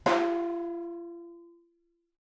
vibraring_v1_rr2.wav